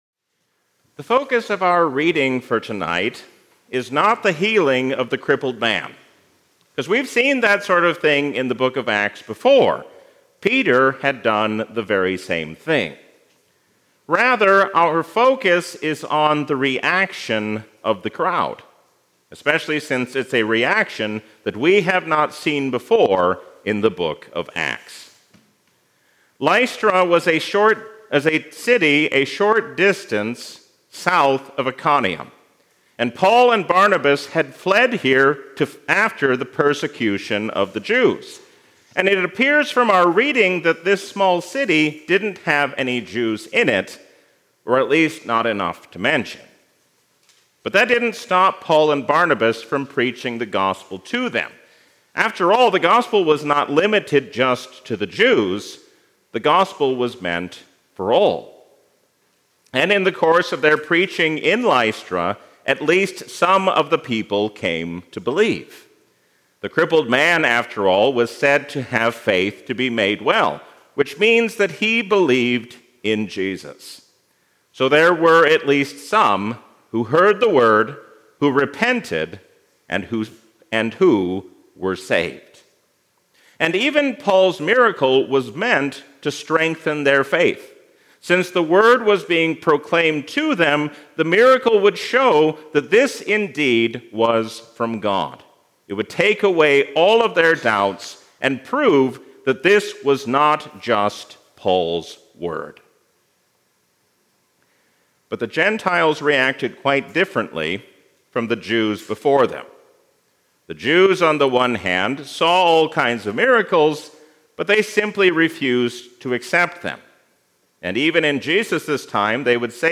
A sermon from the season "Trinity 2025."